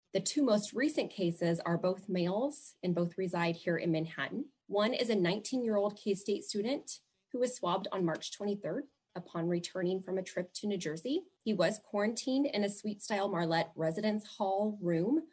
During the daily Riley County Health Department press conference, Riley County Health Director Julie  Gibbs gave an update on the status of COVID-19 in Manhattan. Gibbs gave an update on the two new cases in Riley County.